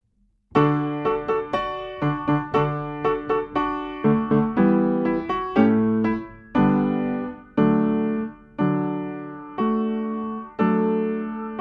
I część: 60 BMP  i 70 BMP
Nagrania dokonane na pianinie Yamaha P2, strój 440Hz
piano